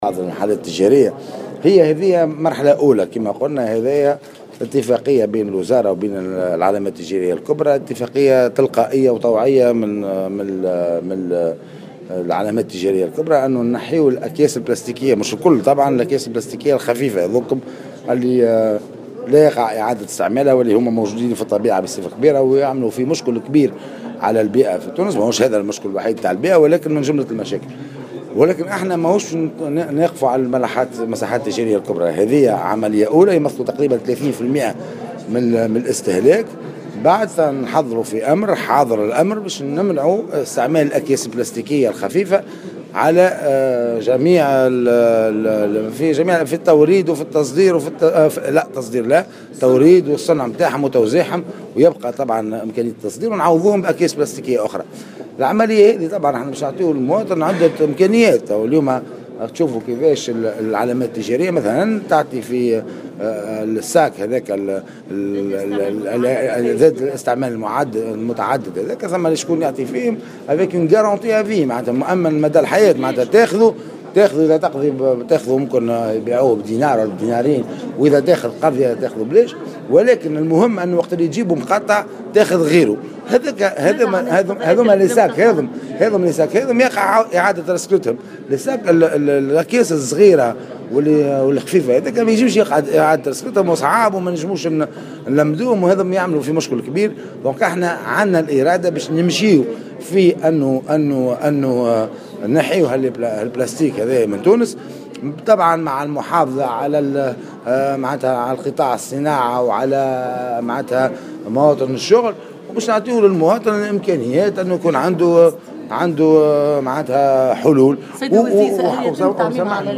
وأوضح وزير الشؤون المحلية والبيئة في تصريح لـ "الجوهرة اف أم" أنه تم الشروع اليوم في تطبيق مشروع منع الأكياس البلاستيكية بصفة طوعية واختيارية وفي مرحلة دون اللجوء الى اجراءات ردعية.